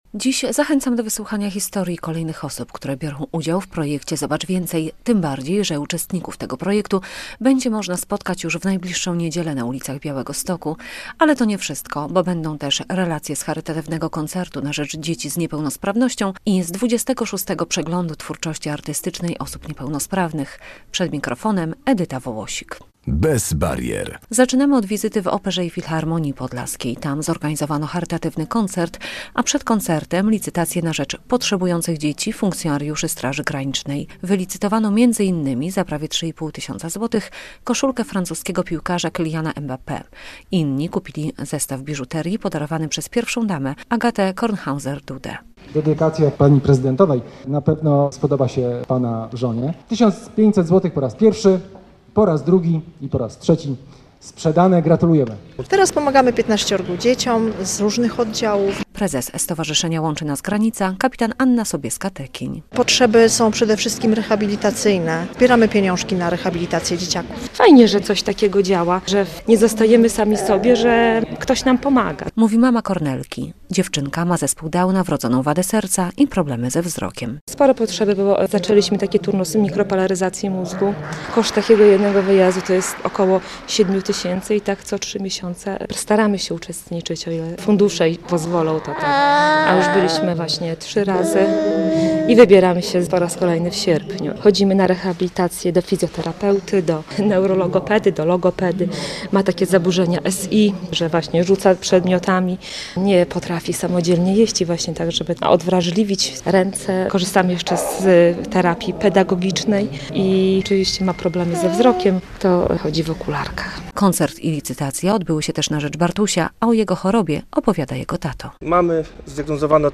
Projekt "Zobacz więcej", relacja z charytatywnego koncertu i Przeglądu Twórczości Artystycznej Osób Niepełnosprawnych